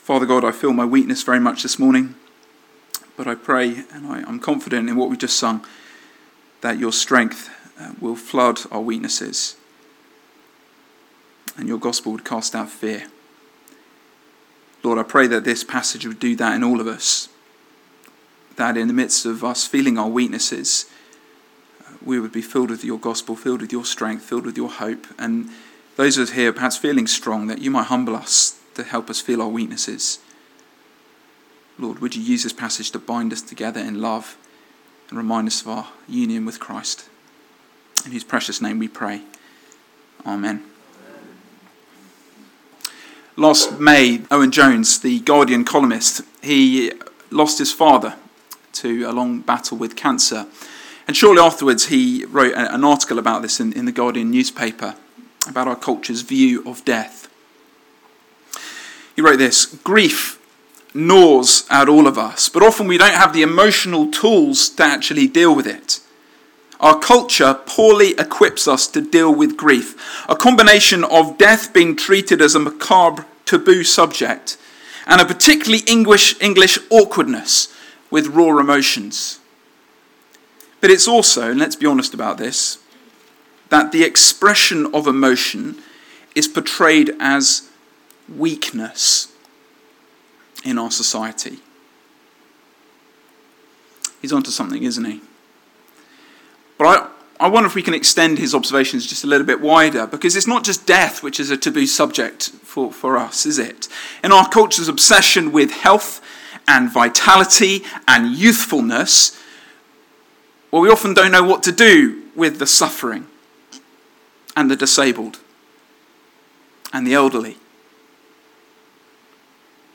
Sermon Video